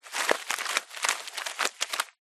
Шум крупной рыбы на берегу